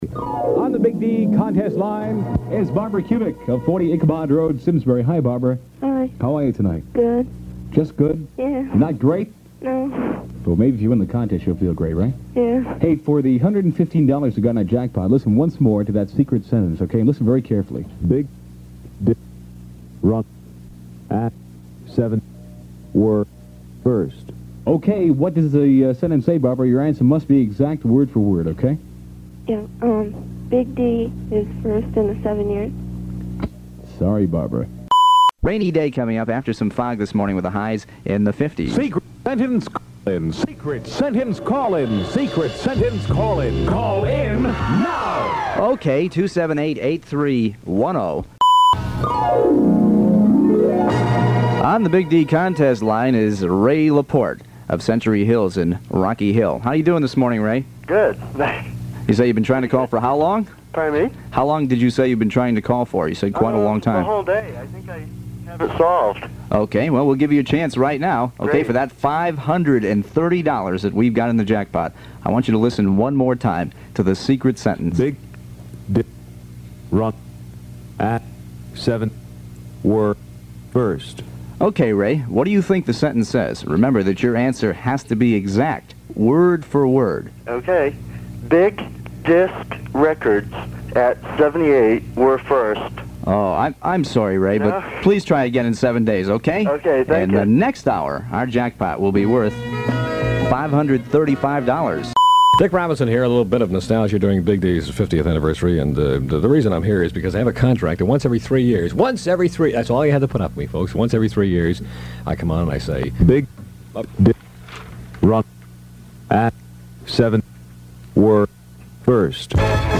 audio - Secret Sentence contest montage The campaign included The Secret Sentence, a variation of the 1960s Secret Sound contest.
Key syllables of his historic statements were edited and listeners had to guess the correct wording to win a cash jackpot.